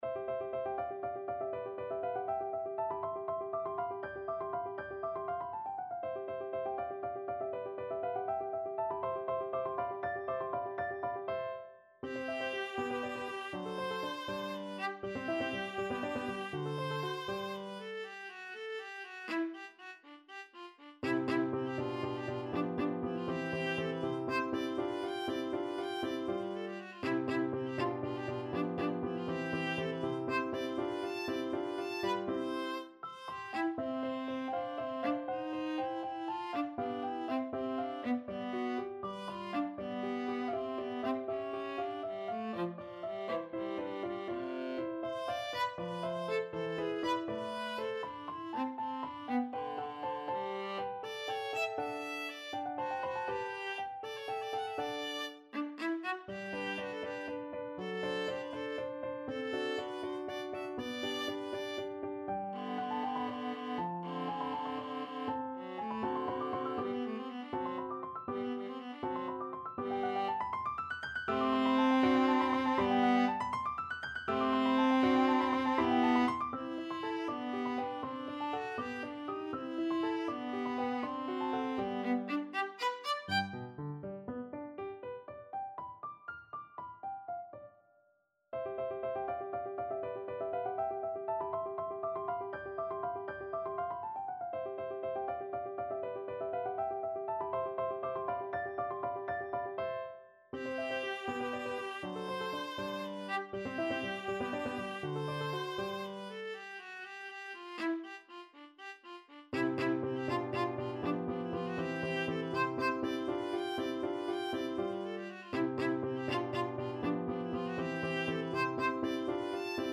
Classical Beethoven, Ludwig van Rondo for Violin and Piano, WoO 41 Viola version
Play (or use space bar on your keyboard) Pause Music Playalong - Piano Accompaniment Playalong Band Accompaniment not yet available transpose reset tempo print settings full screen
~ = 100 Allegro (View more music marked Allegro)
C major (Sounding Pitch) (View more C major Music for Viola )
6/8 (View more 6/8 Music)
Viola  (View more Advanced Viola Music)
Classical (View more Classical Viola Music)